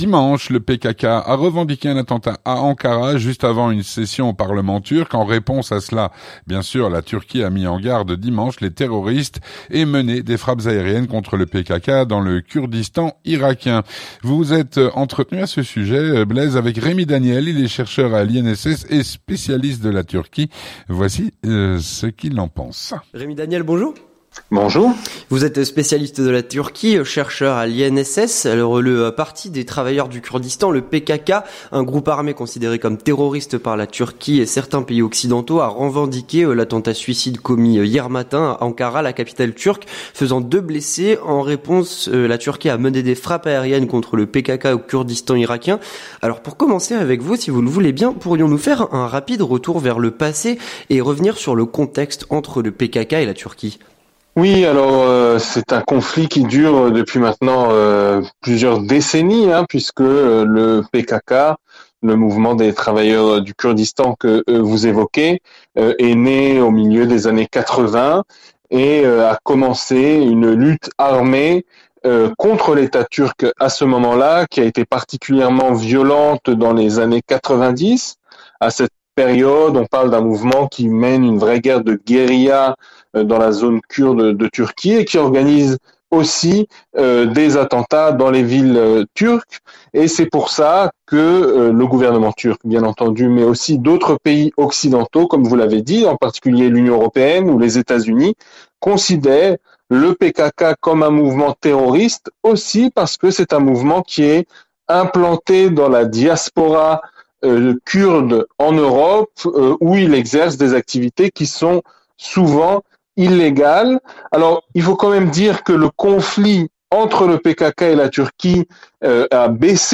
L'entretien du 18H - Attentat à Ankara revendiqué par le PKK.